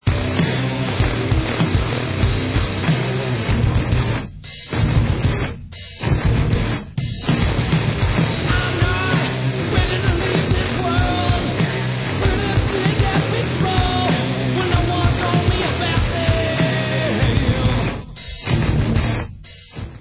Rock/Alternative Metal